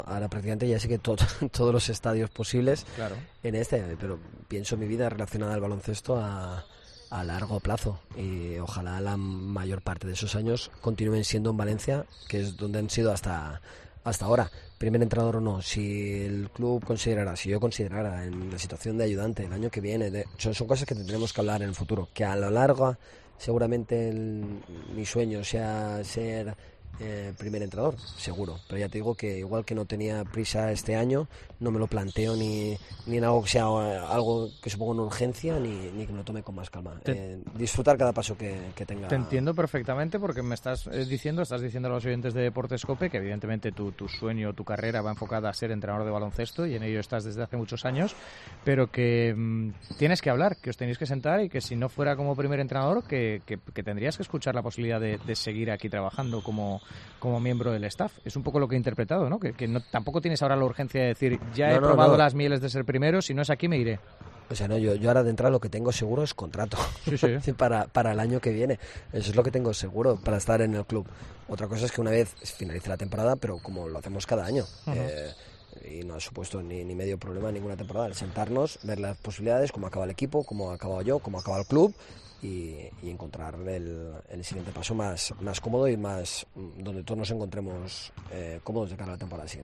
Deportes COPE Valencia ENTREVISTA